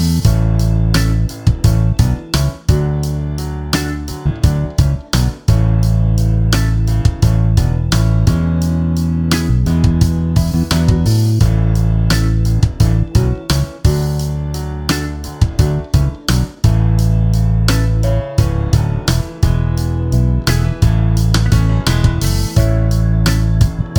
no Backing Vocals Soundtracks 4:19 Buy £1.50